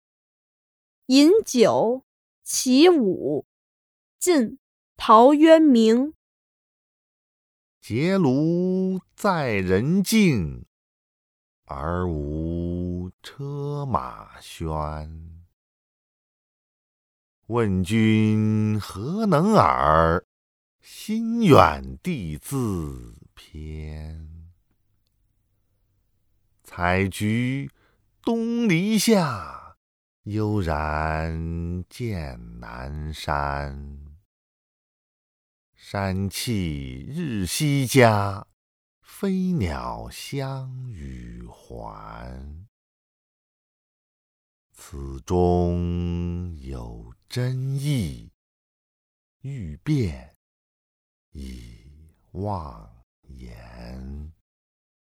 ［晋］陶渊明 《饮酒》 （其五）（读诵）